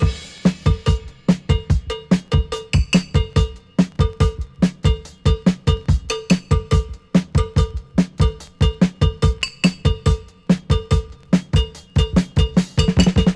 • 144 Bpm High Quality Breakbeat B Key.wav
Free drum loop sample - kick tuned to the B note. Loudest frequency: 712Hz
144-bpm-high-quality-breakbeat-b-key-Atv.wav